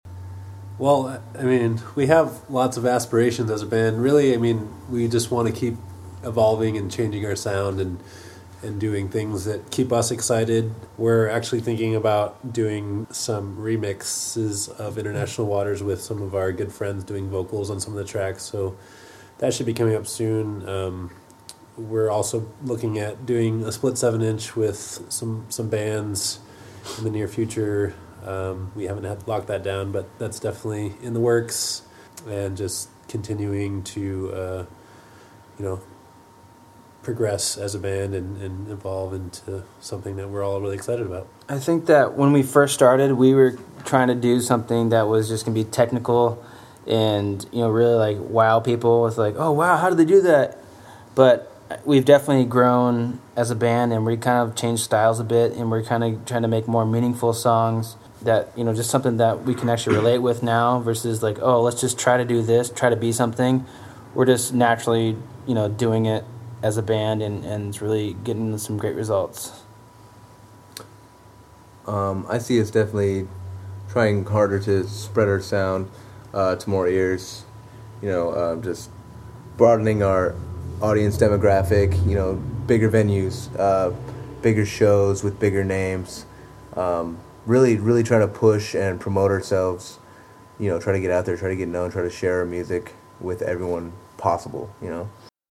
YOU MAY DIE IN THE DESERT INTERVIEW- October 2012